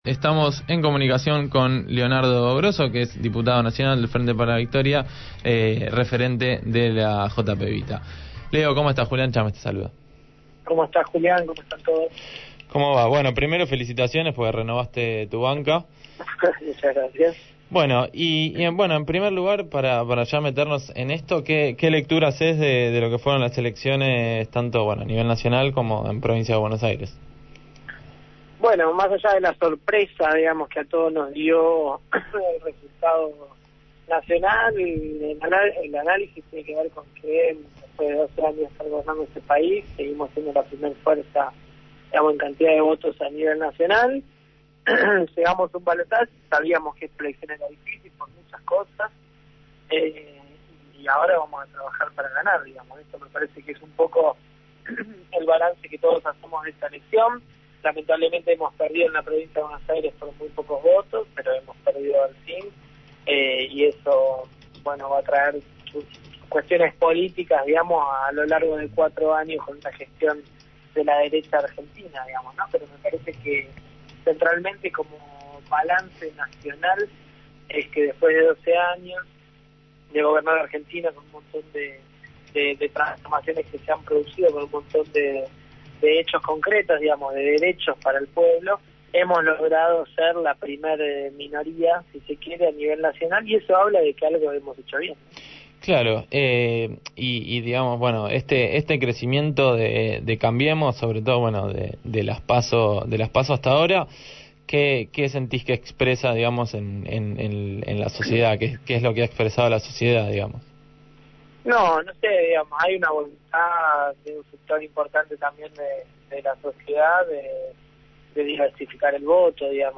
Sin Zonceras dialogó con Leonardo Grosso, diputado nacional del Frente para la Victoria y referente del Movimiento Evita sobre el proceso electoral que continúa.